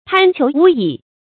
贪求无已 tān qiú wú yǐ
贪求无已发音
成语注音ㄊㄢ ㄑㄧㄡˊ ㄨˊ ㄧˇ